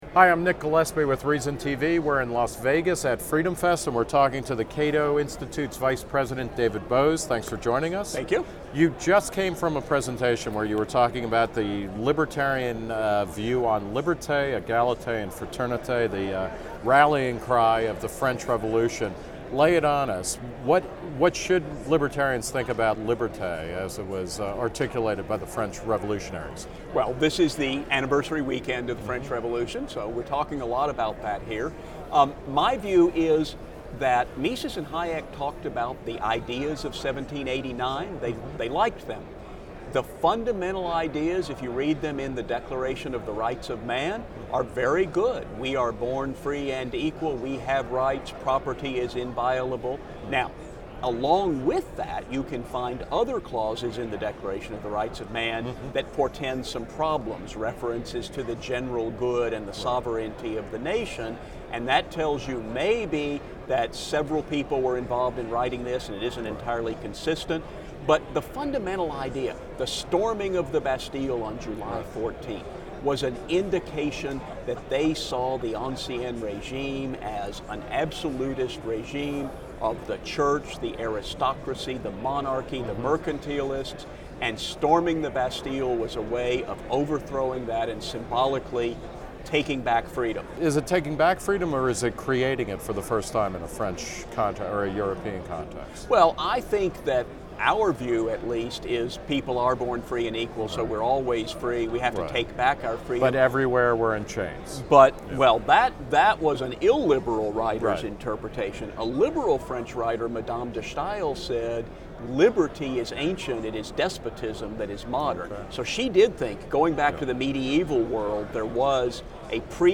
Held each July in Las Vegas, FreedomFest is attended by around 2,000 libertarians and advocates of limited government.